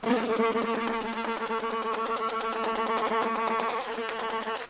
fly3.ogg